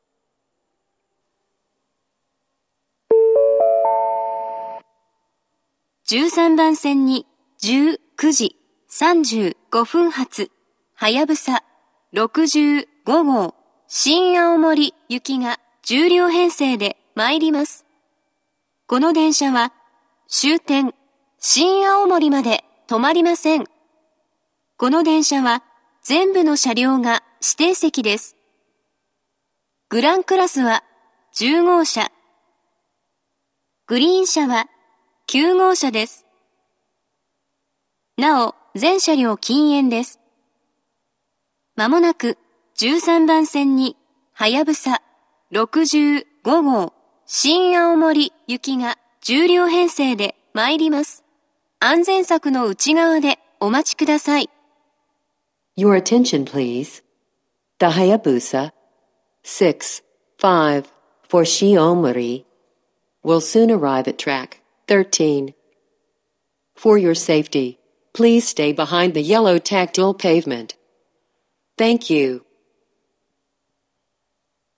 １３番線接近放送